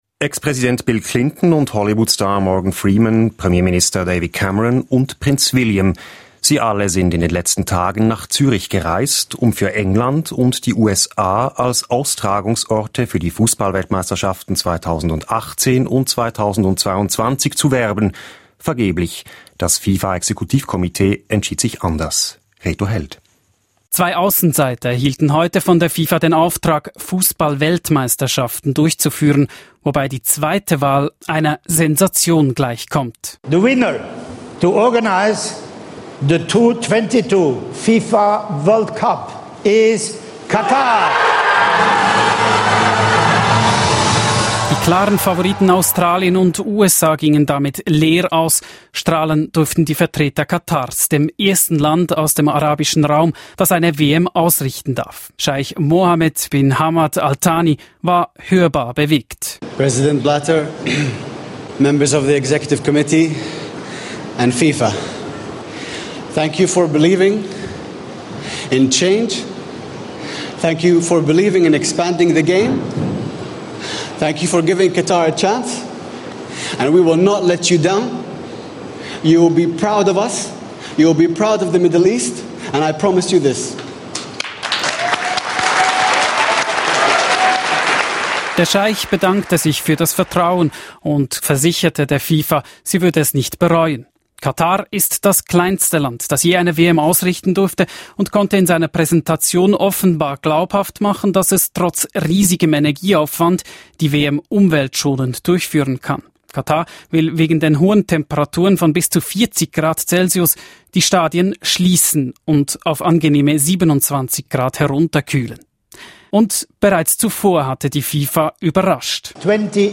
im Gesräch